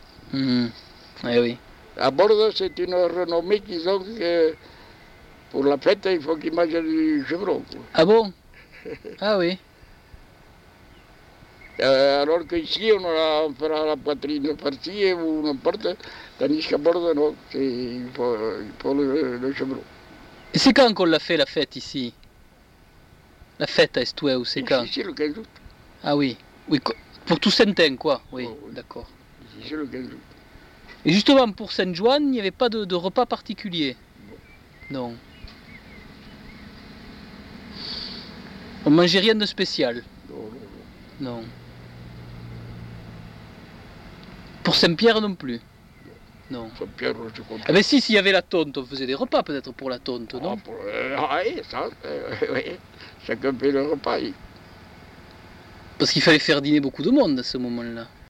Aire culturelle : Couserans
Lieu : Estouéou (lieu-dit)
Genre : témoignage thématique